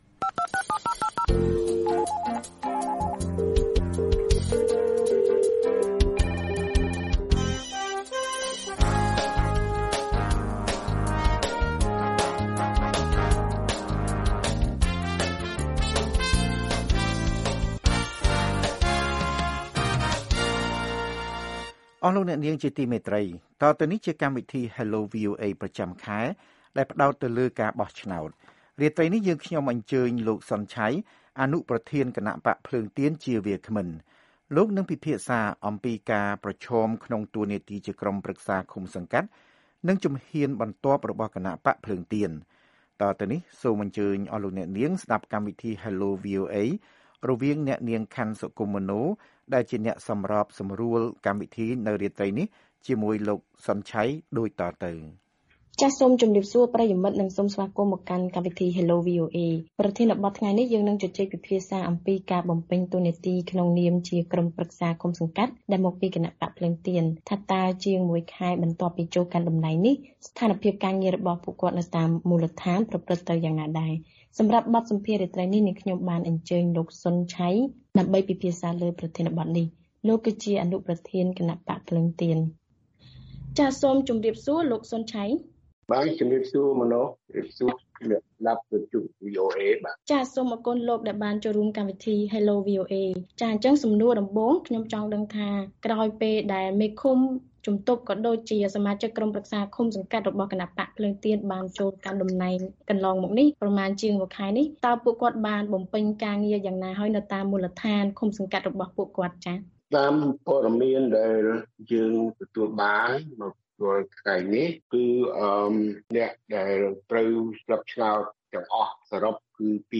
នេះបើតាមលោក សុន ឆ័យ អនុប្រធានគណបក្សភ្លើងទៀន ផ្តល់បទសម្ភាសដល់វីអូអេ។